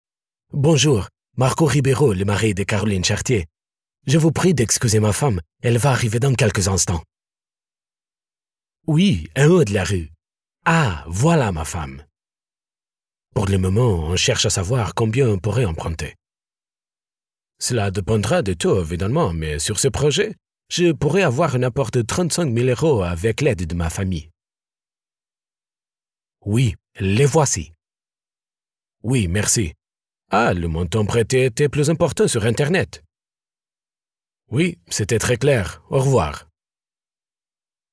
Male
Adult (30-50)
French With Portuguese Accent
Words that describe my voice are Portuguese Voice Over, Strong, Warm.
1103PORTUGUESE_FRENCH_ACENT.mp3